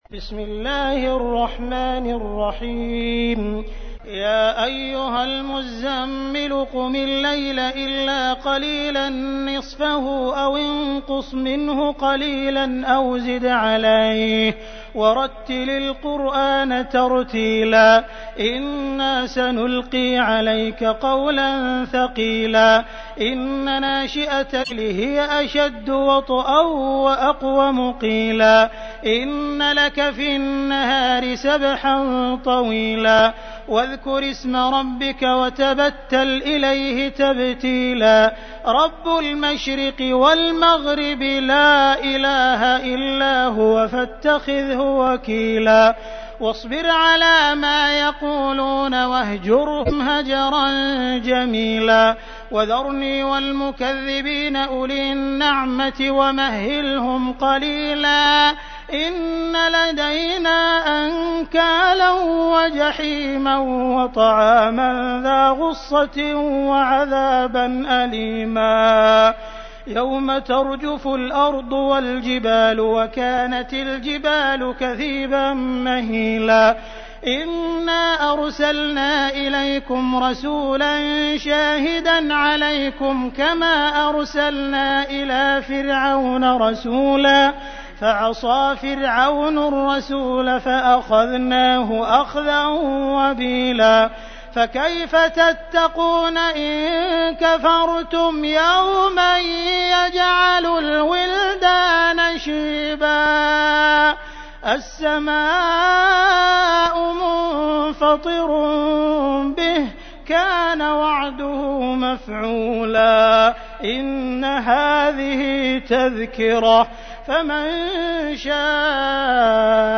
تحميل : 73. سورة المزمل / القارئ عبد الرحمن السديس / القرآن الكريم / موقع يا حسين